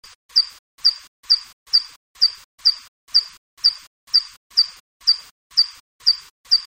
Ecoutez son cri (il est beaucoup plus aigu dans la nature).
cri pipistrellus.mp3